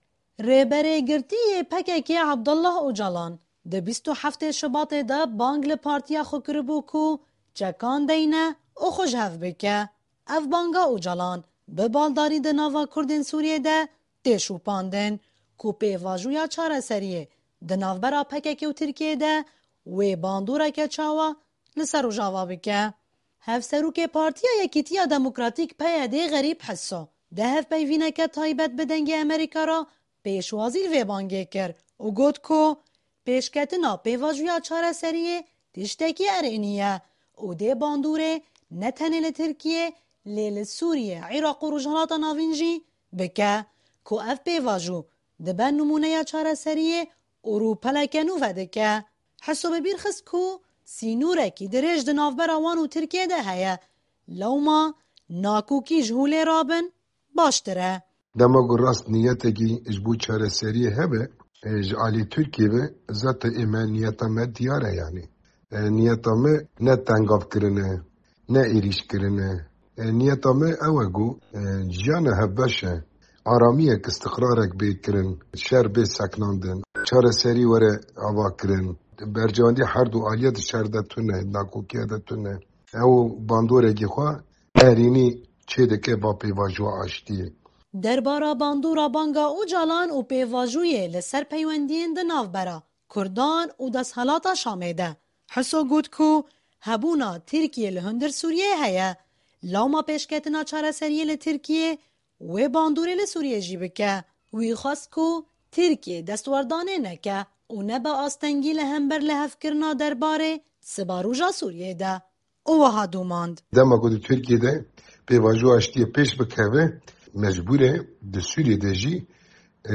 Hevserokê Partîya Yekîtîya Demokrtaîk (PYD) Xerîb Hiso di hevpeyvîneke taybet bi Dengê Amerîka re pêşwazî li vê bangê kir û got ku "Pêşketina pêvajoya çareserîyê tiştekî erênî ye, dê bandorê ne tenê li Tikrîyê, lê li Sûrîyê, Îraq û rojhilata navîn jî bike".